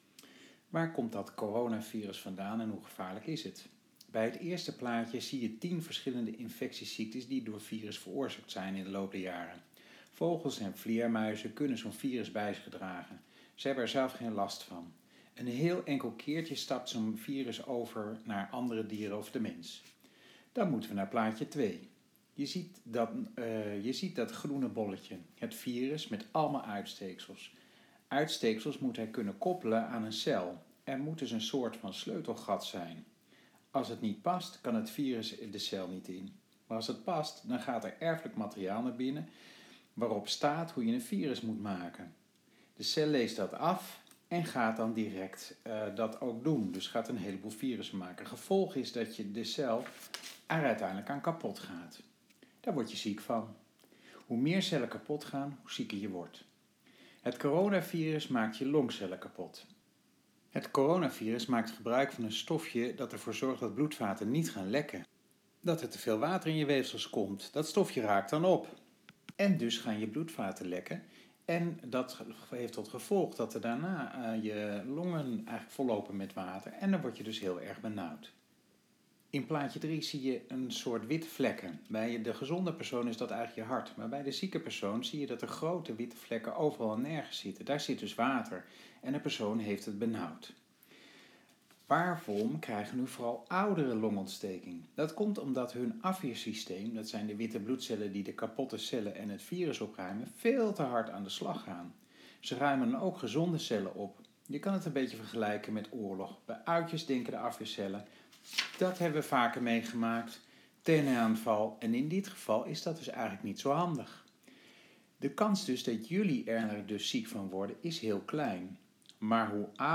Voor elk plaatje heb ik een tekstje ingesproken.